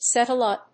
アクセントséttle úp